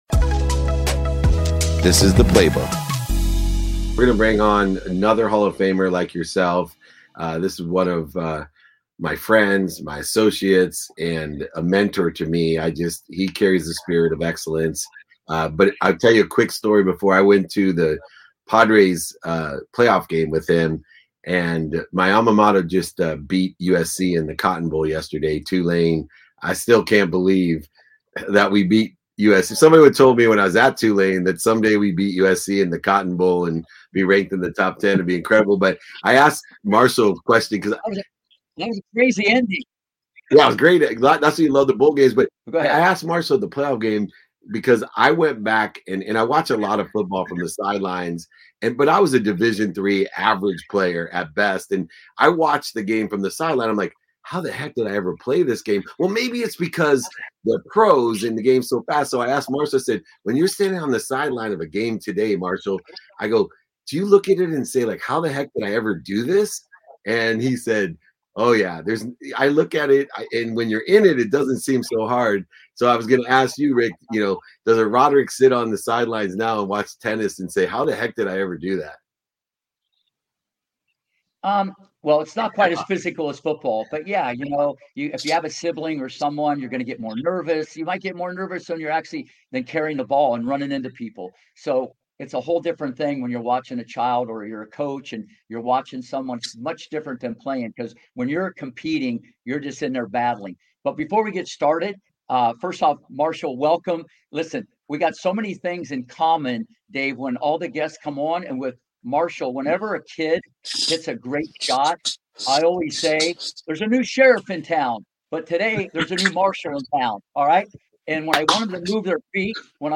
On today’s episode of the Game, Set, Life series with legendary tennis coach Rick Macci, we’re joined by one of the greatest running backs of all time, NFL Hall of Famer Marshall Faulk. During our dialogue, Marshall shared insights on how he’s translated his success on the gridiron into the business world, his preparation for high-pressure situations under the brightest lights, and his thoughts on the Damar Hamlin incident.